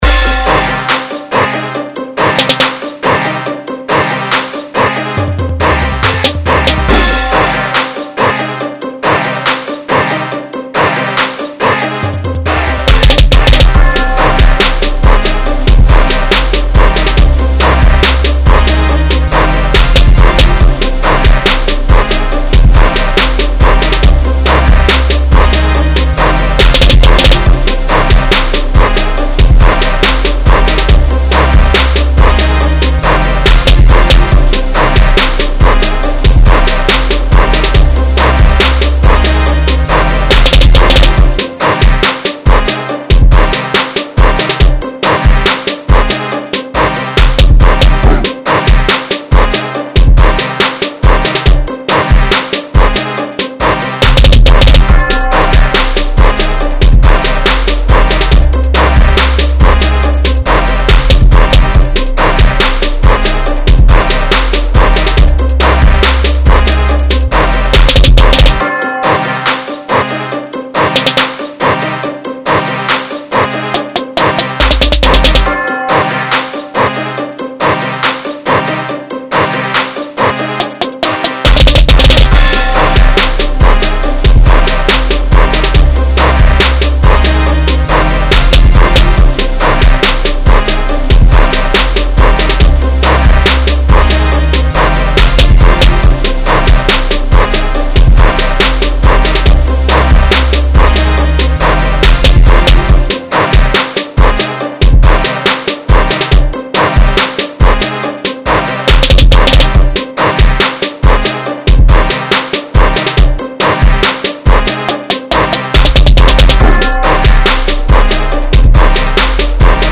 dance/electronic
tHIS instumental will get any club bouncing!!!
Dancehall
RnB
Rap